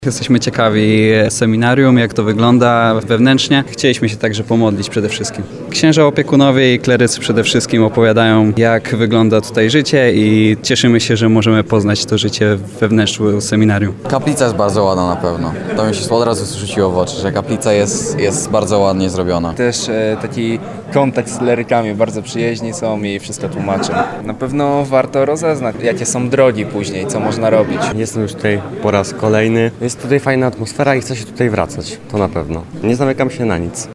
Była wspólna modlitwa, mecz piłki nożnej i zwiedzanie. W Wyższym Seminarium Duchownym w Tarnowie odbył się dzień otwarty dla młodych mężczyzn, którzy chcieli przekonać się, jak funkcjonuje uczelnia.